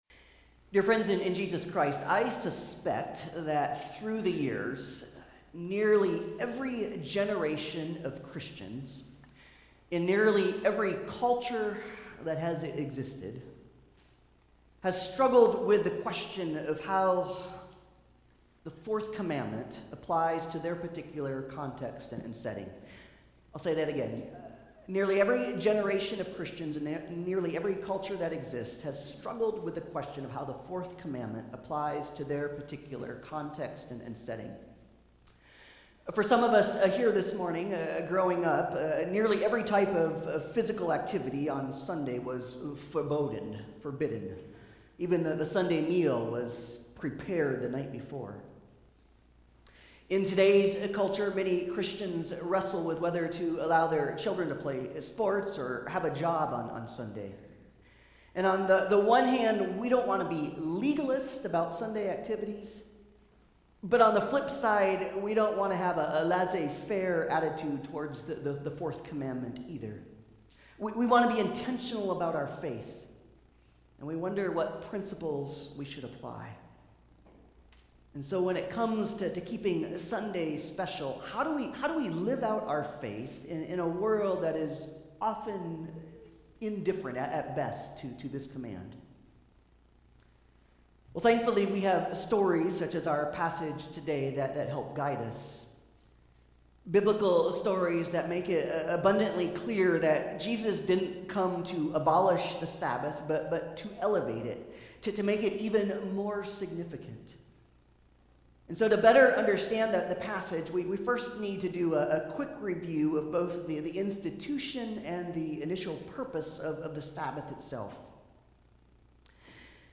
Passage: Exodus 20:8-11, Mark 2:23-3:6, Romans 12:1-2 Service Type: Sunday Service